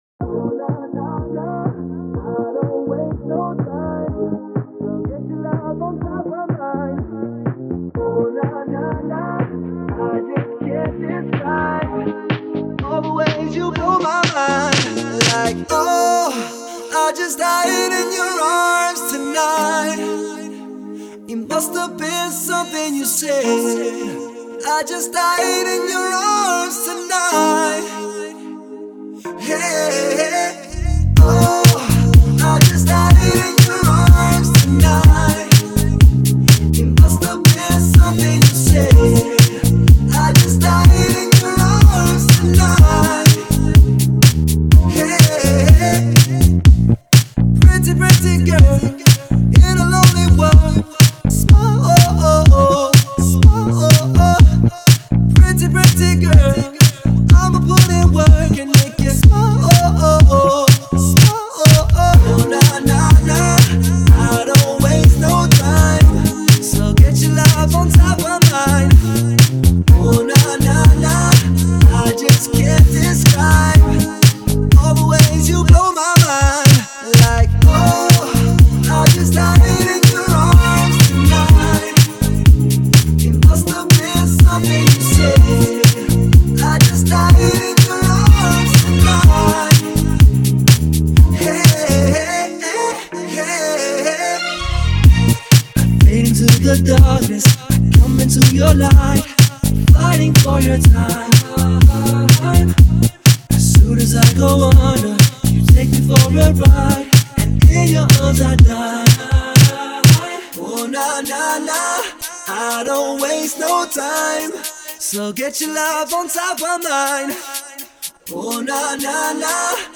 это зажигательная поп-музыка с элементами R&B